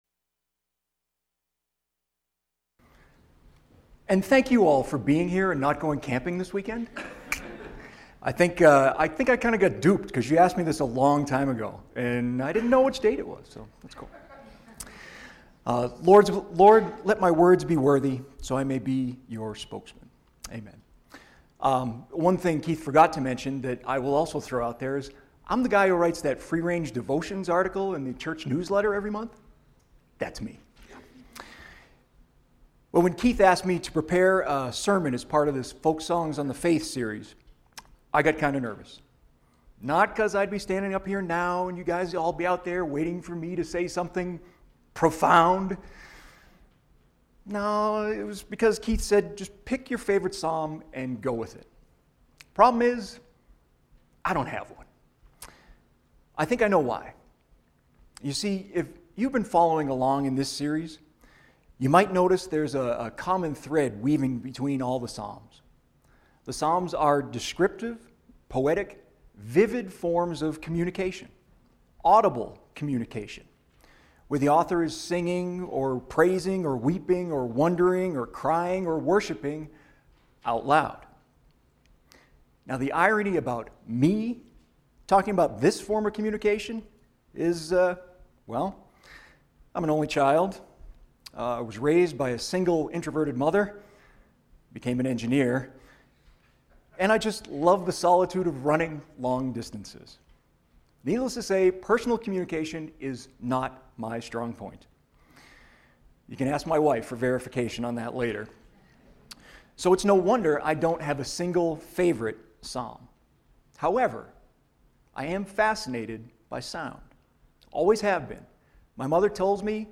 Sermon_Sound-of-the-Psalms.mp3